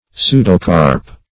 Pseudocarp \Pseu"do*carp\, n. [Pseudo- + Gr.